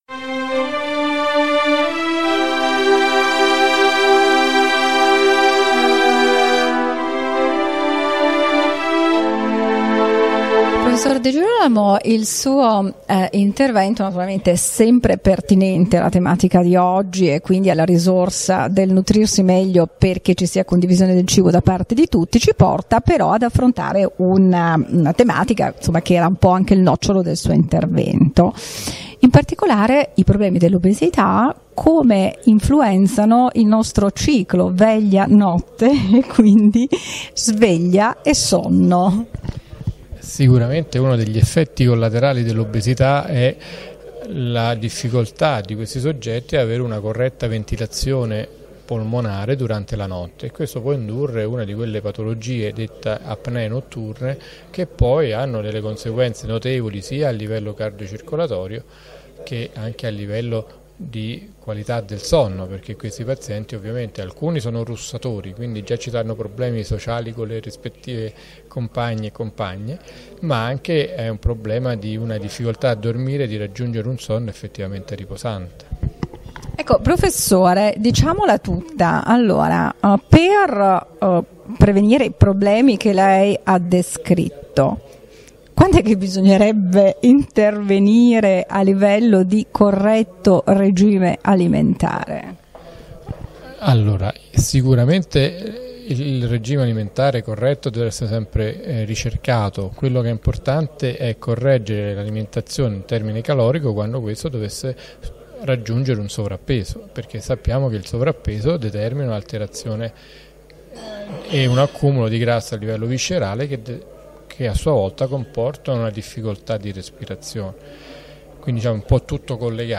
Intervista
Simposio "Nutrirsi meglio, nutrirsi tutti" Dono e condivisione per un nuovo rinascimento, Roma 15 ottobre 2015 presso Palazzo Ferraioli